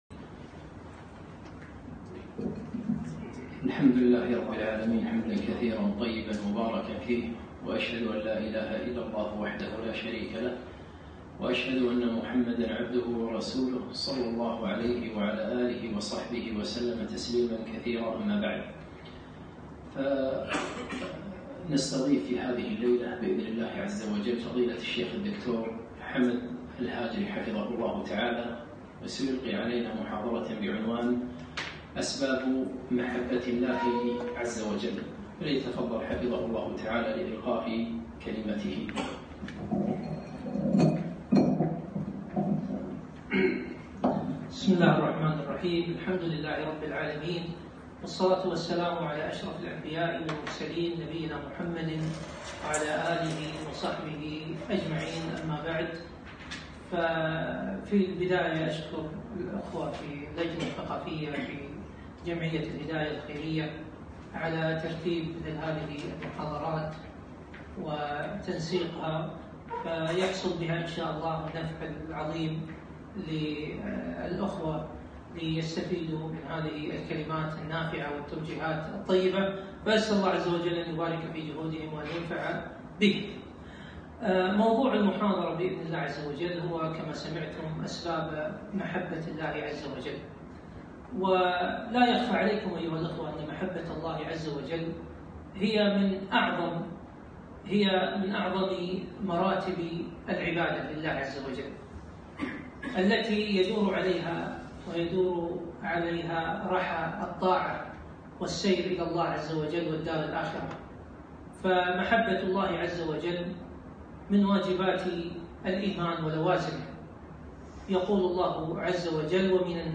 محاضرة - أسباب محبة الله عزوجل